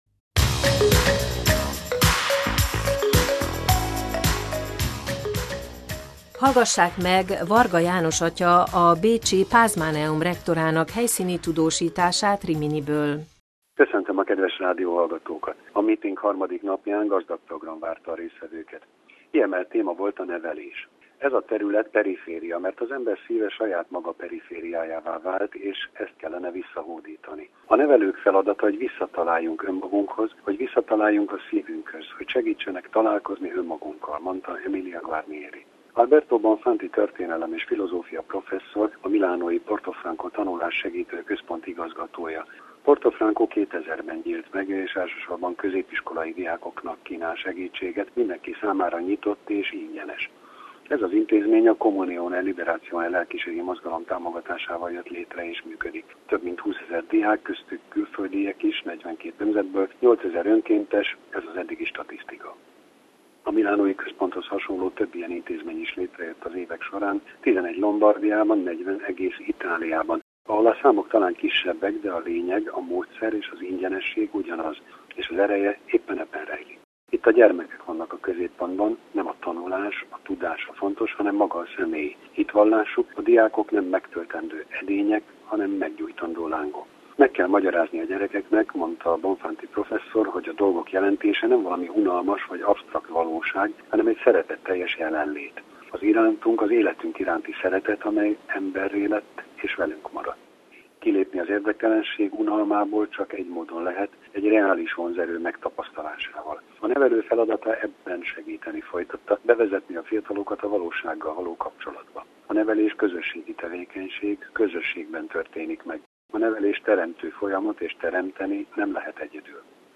helyszíni tudósítása Riminiből